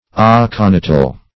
Aconital \Ac`o*ni"tal\, a. Of the nature of aconite.